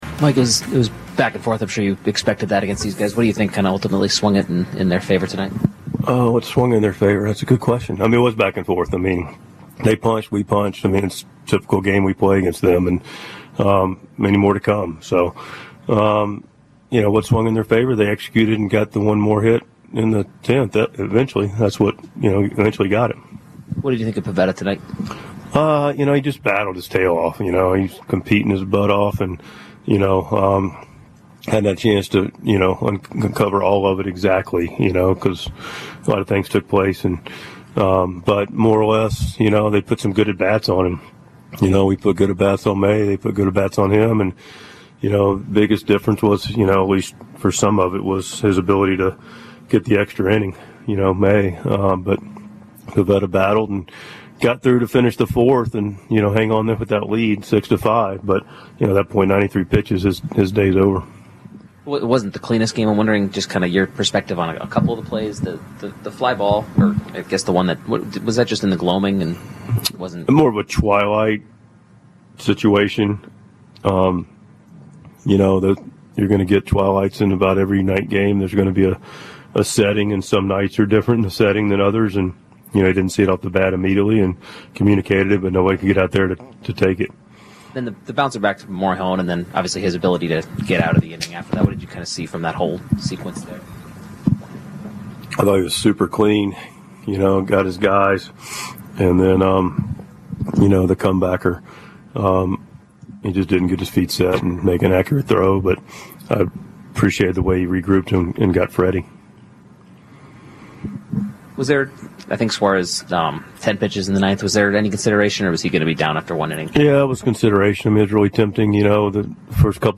Mike Shildt's postgame comments after Monday's 8-7 loss to the Dodgers.